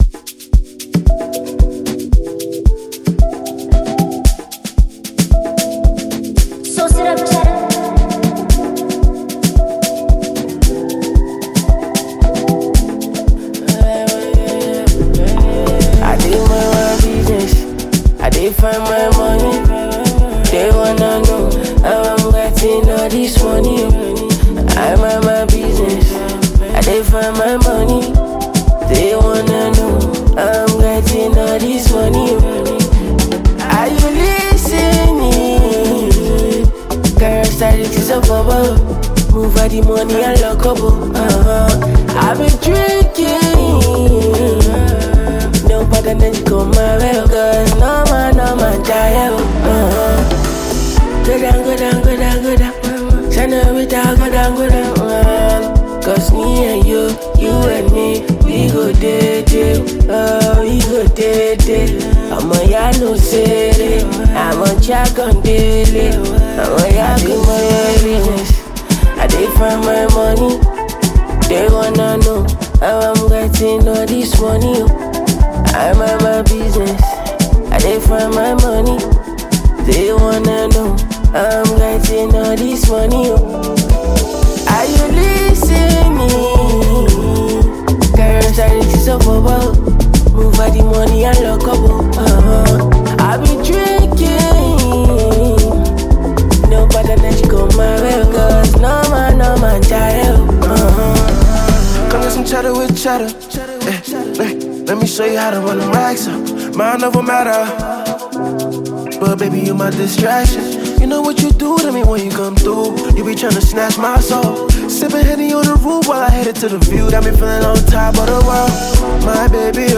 A Bold New Anthem of Focus, Flex, and Freedom.
Afrobeats